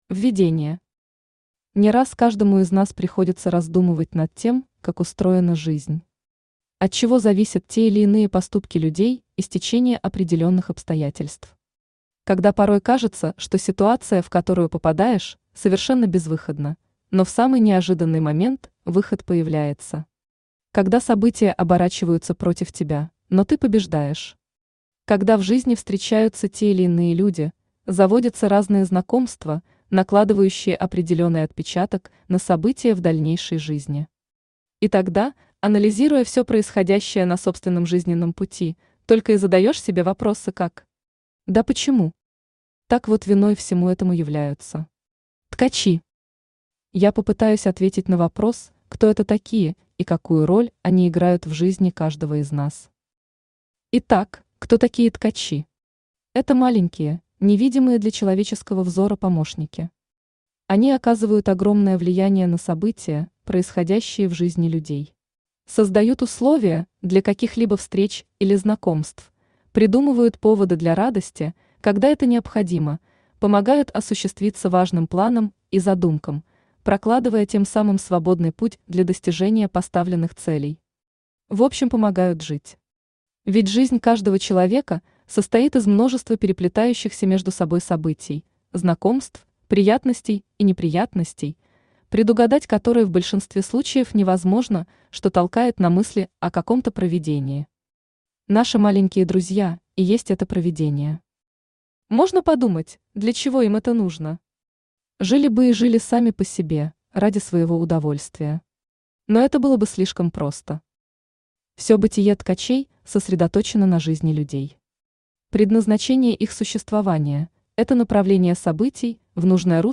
Аудиокнига Незримые путеводители по человеческим судьбам | Библиотека аудиокниг
Aудиокнига Незримые путеводители по человеческим судьбам Автор Илона Шлотгауэр Читает аудиокнигу Авточтец ЛитРес.